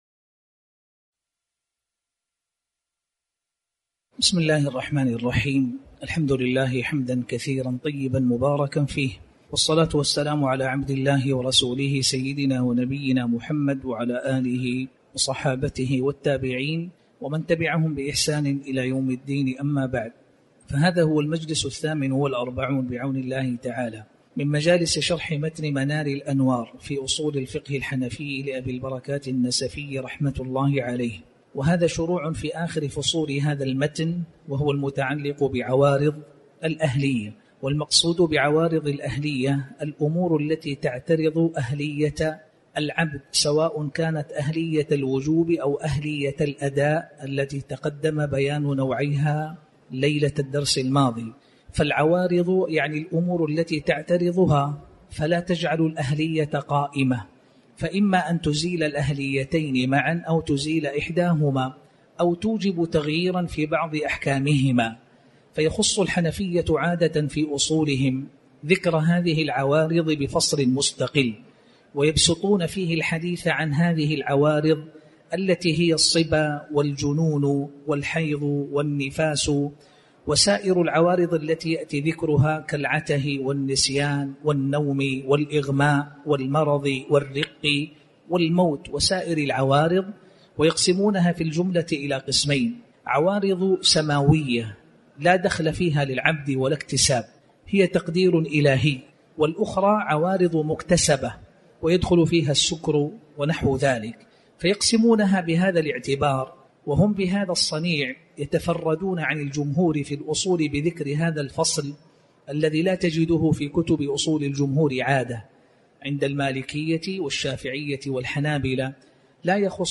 تاريخ النشر ١٥ جمادى الآخرة ١٤٤٠ هـ المكان: المسجد الحرام الشيخ